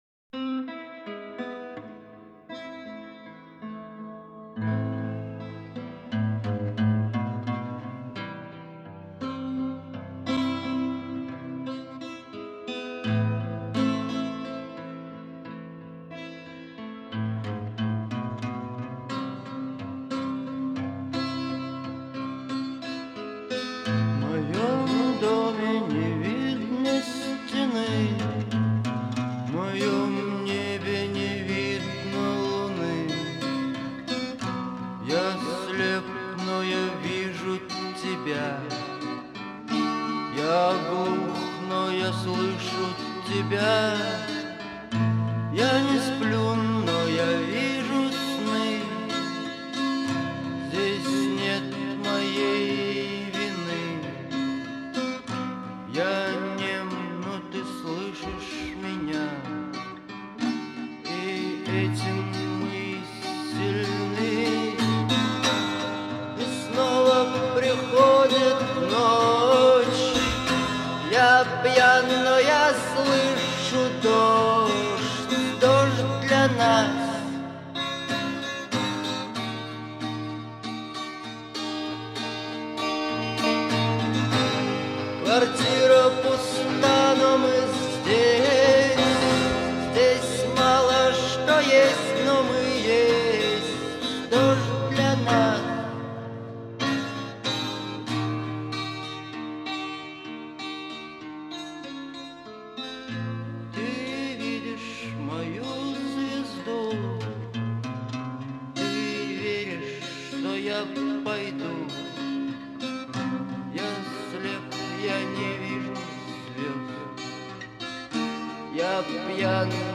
это яркий пример постпанка с меланхоличным настроением.